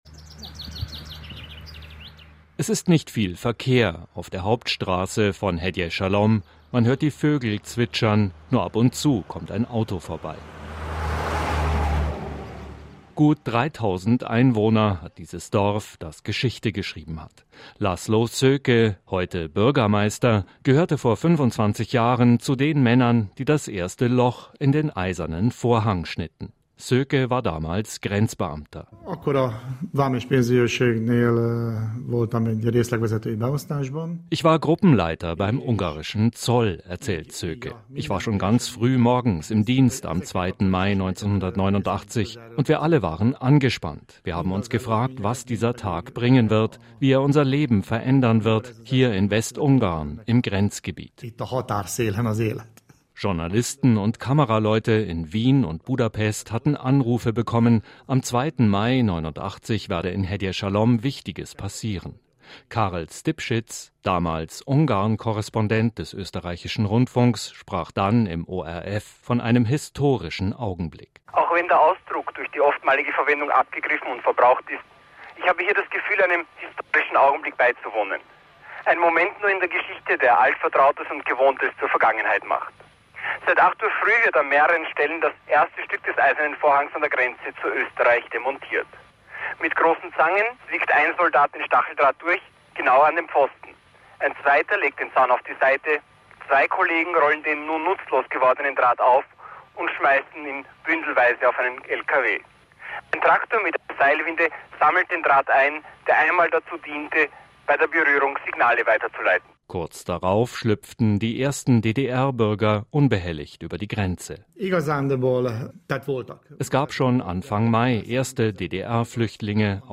Was folgte, war historisch: ungarische Grenzer schnitten das erste Loch in den Eisernen Vorhang. Mit dabei: Laszlo Szöke, damals Grenzbeamter, heute Bürgermeister von Hegyeshalom. Lachend und ernst zugleich blickt er zurück und zieht Bilanz.